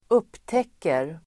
Uttal: [²'up:tek:er]